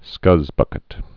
(skŭzbŭkĭt)